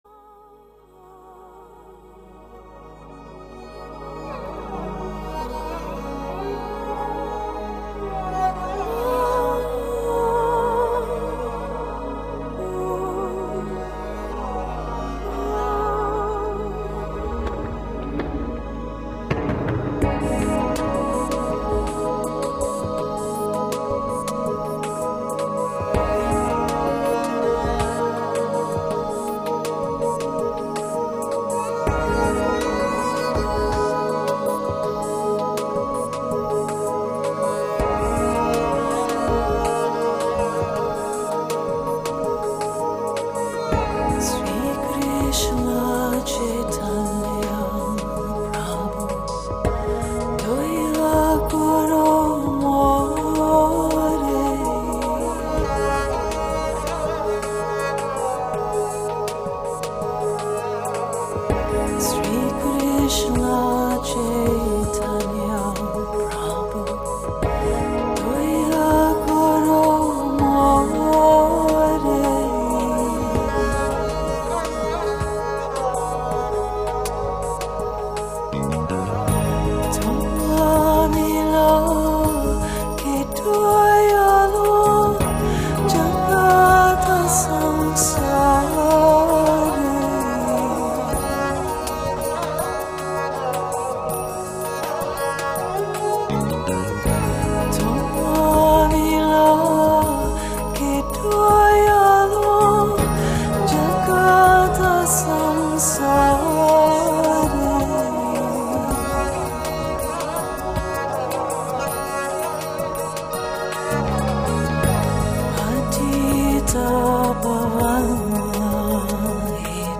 meditative and ethereal instrumentation
achingly beautiful vocals
the soothing, hypnotic aspects of Indian spiritual music